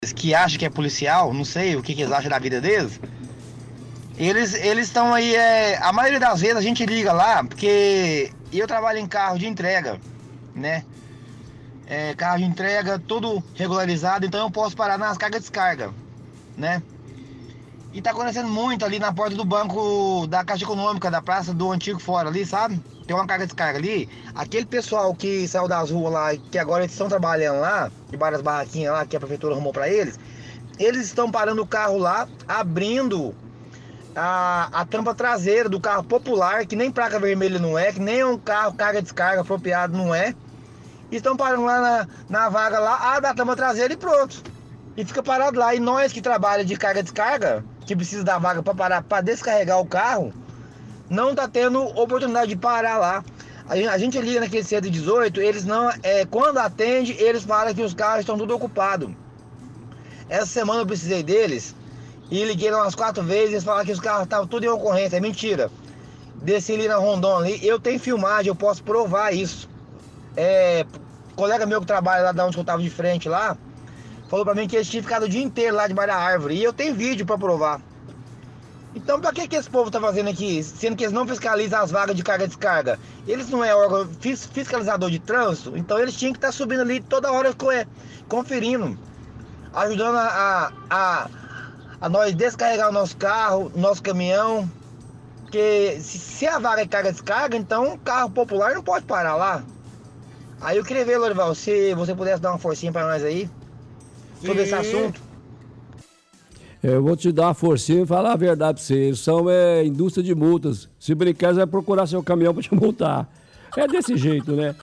– Ouvinte motorista de carga e descarga reclama de agentes da SETTRAN que estão ocupando vagas de carga e descarga, fala que ligam no 118 mas não atendem ou que as viaturas estão todas ocupadas, diz que é mentira porque gravou agentes descansando em viatura.